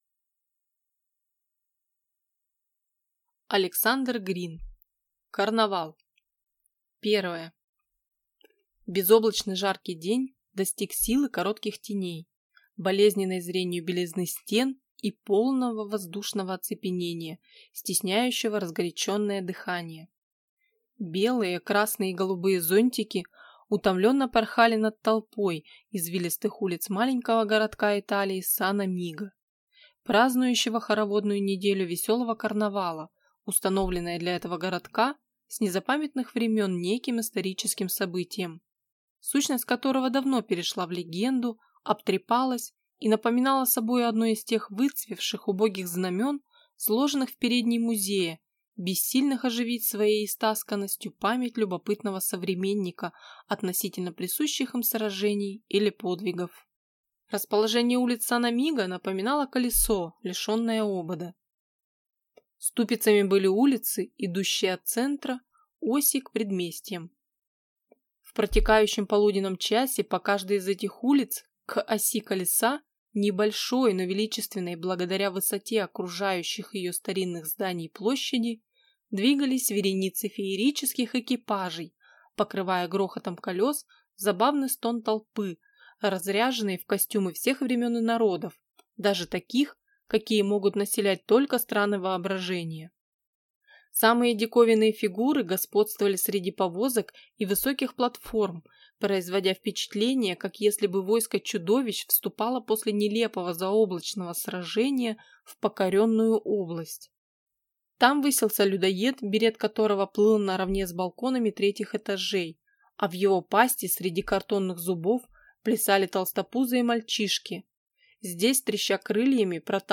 Аудиокнига Карнавал | Библиотека аудиокниг